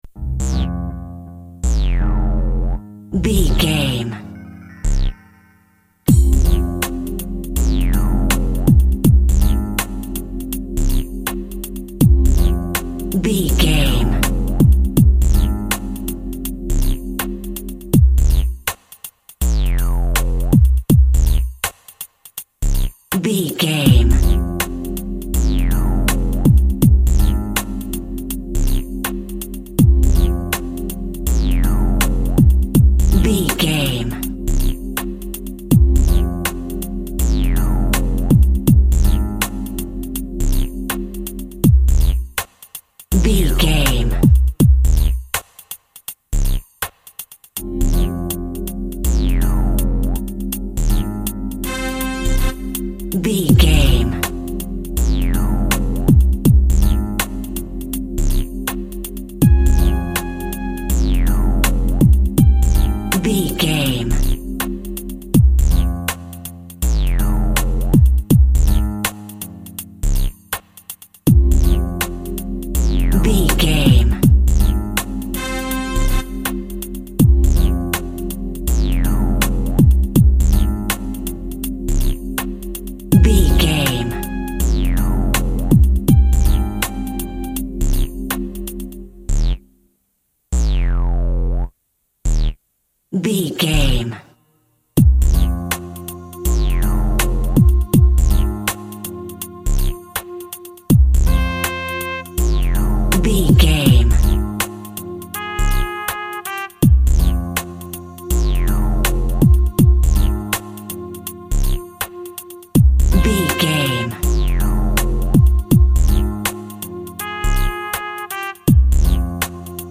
Aeolian/Minor
hip hop
turntables
synth lead
synth bass
hip hop synths